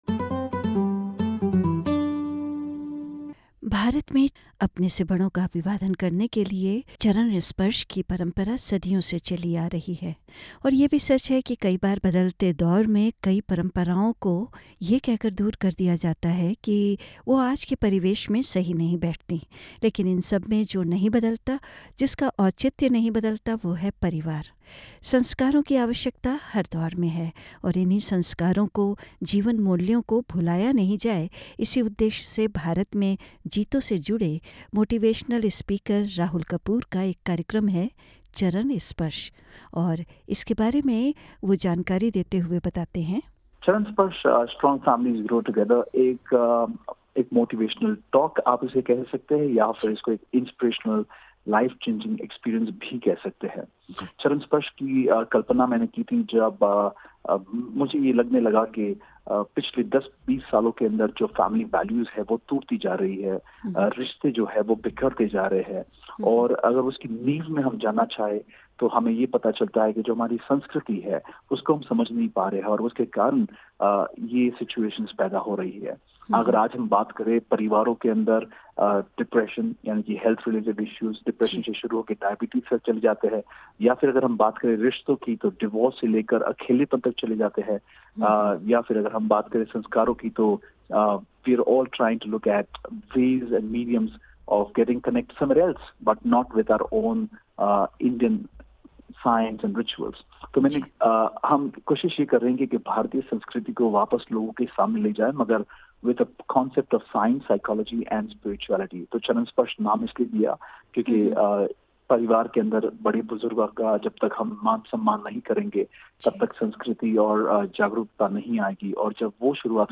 a motivational speaker explains the science, spirituality and the psychology behind the practice of ‘Charan Sparsh’ which is considered as a mark of respect for elders.